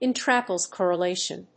intraclass+correlation.mp3